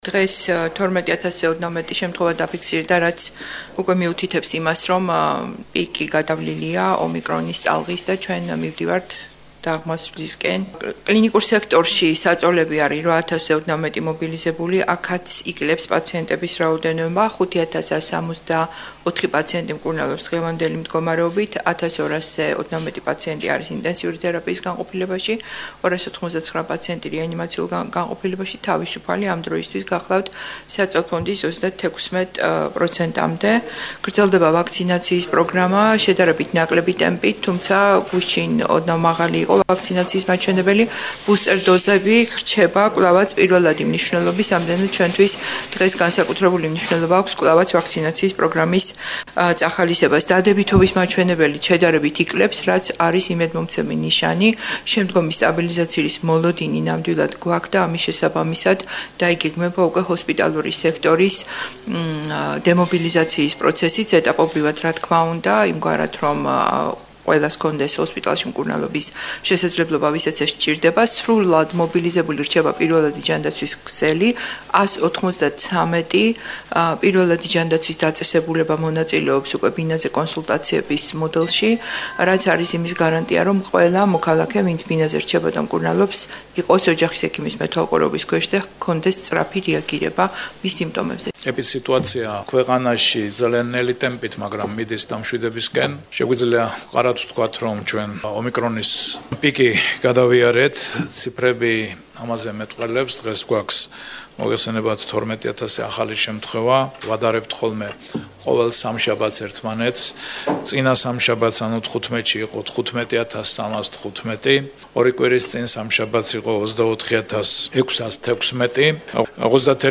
თამარ გაბუნიას და ამირან გამყრელიძის ხმა